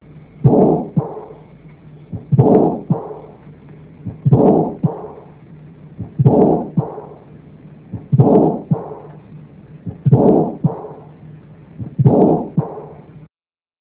This Page contains some of the sounds of pathological Mitral and Aortic Valve Lesions.
AS and SM and Diastolic Murmur(DM) and LA2 and Atrial Sound